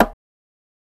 MZ Bongo [Dro Bongo #1].wav